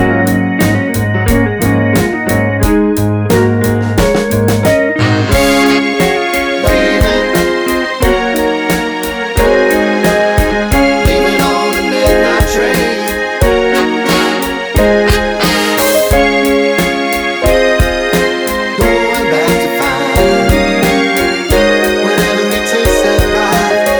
No Bass Soul / Motown 4:31 Buy £1.50